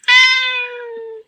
猫の甘え声１
cat_sweet_voice1.mp3